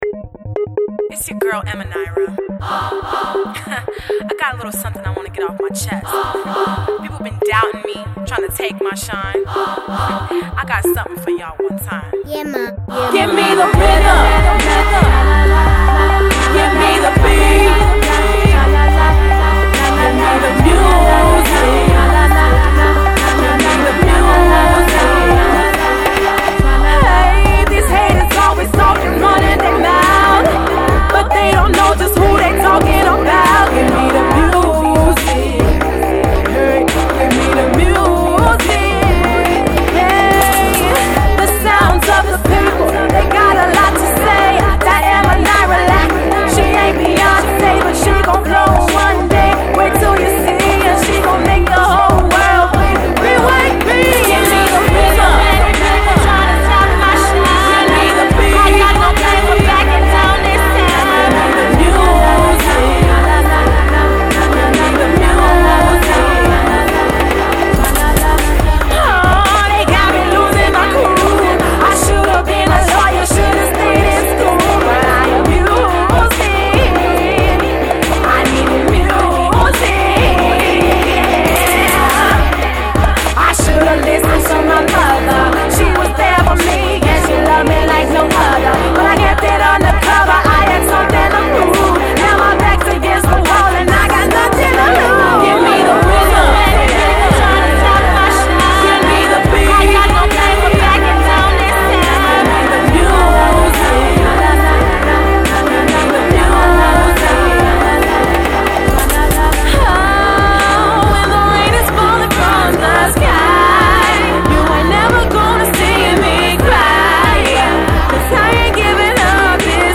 honest and very frank lyrics anyone can easily relate to